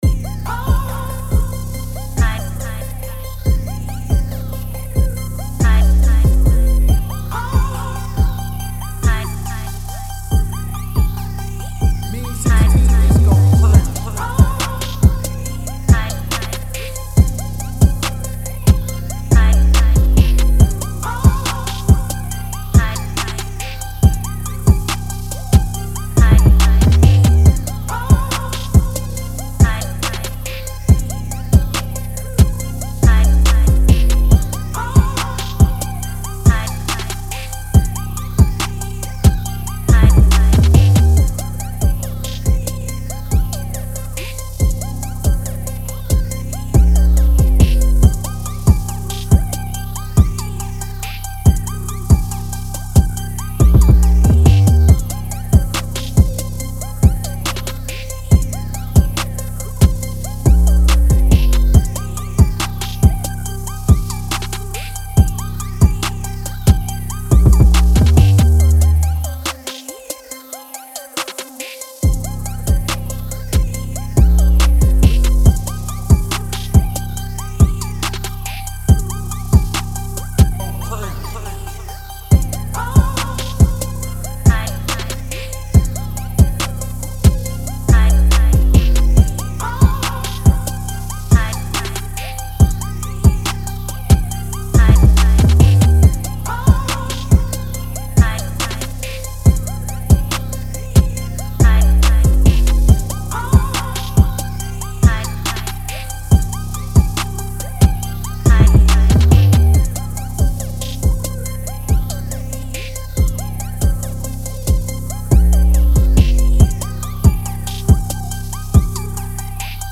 TRAP
C-Min 140-BPM